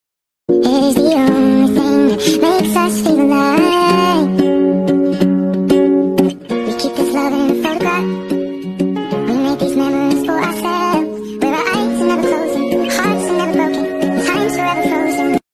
hehe sound effects free download